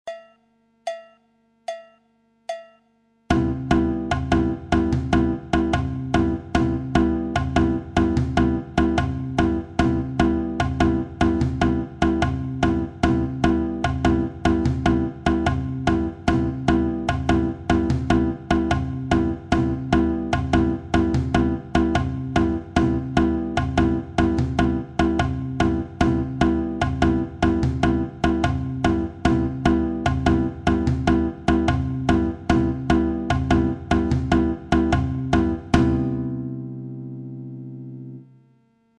La bossa nova figure 1
figure guitare seule, avec le tambourim et le surdo.
la batida de base